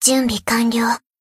贡献 ） 分类:蔚蓝档案语音 协议:Copyright 您不可以覆盖此文件。
BA_V_Shiroko_Ridingsuit_Battle_Buffself_1.ogg